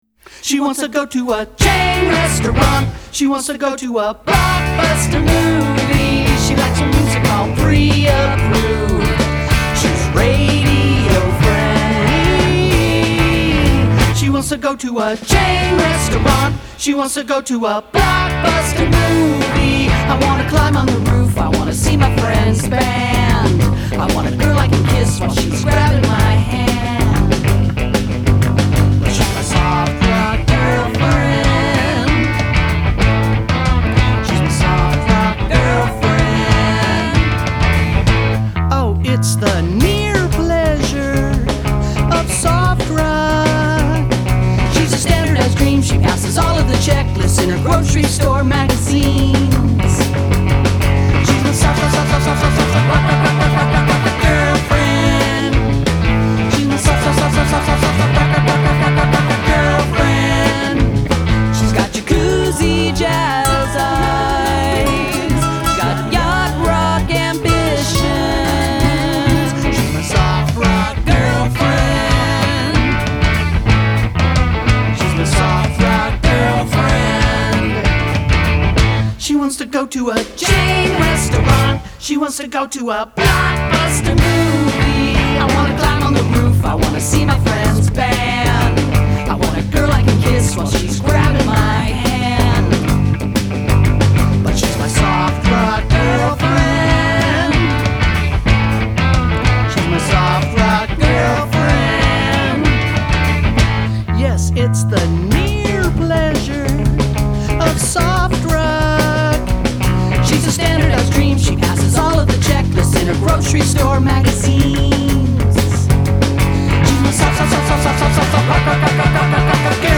these guys are wonderfully weird.